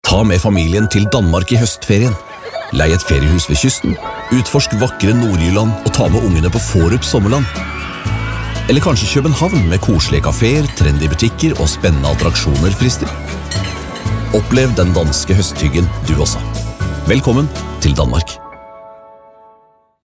Comercial, Profundo, Natural, Seguro, Amable
Corporativo